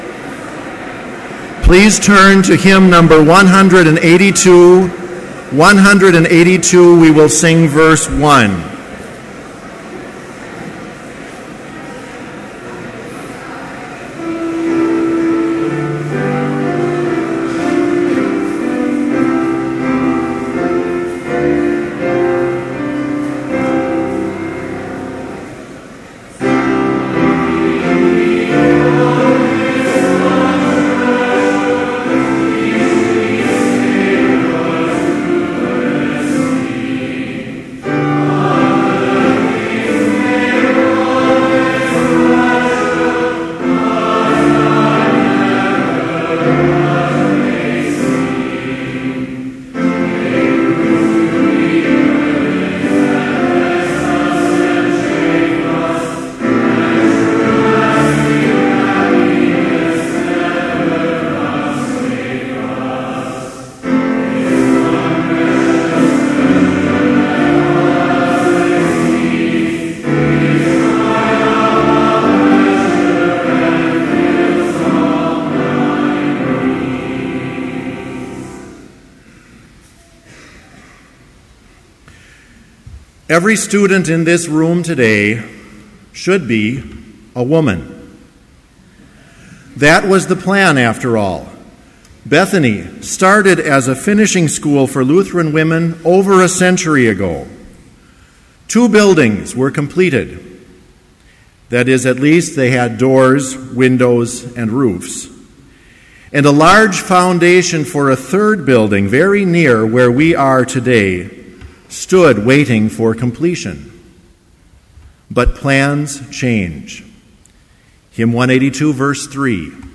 Chapel in Trinity Chapel, Bethany Lutheran College
Complete service audio for Chapel - August 28, 2013